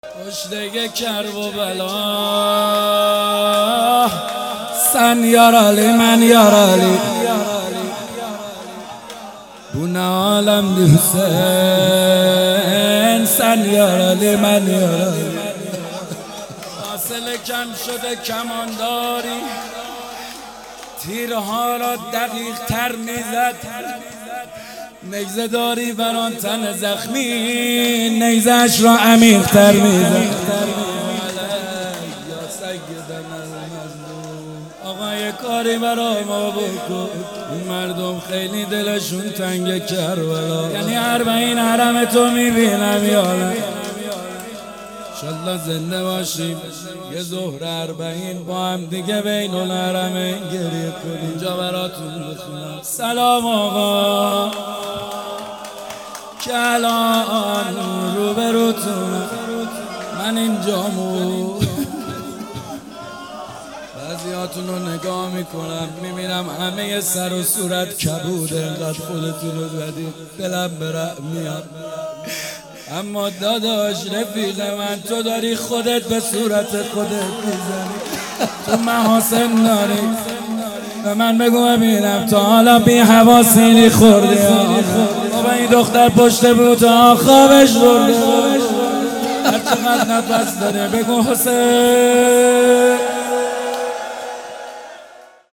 مداحی محمدحسین حدادیان | هیئت عشاق الرضا (ع) تهران |محرم 1399 | پلان 3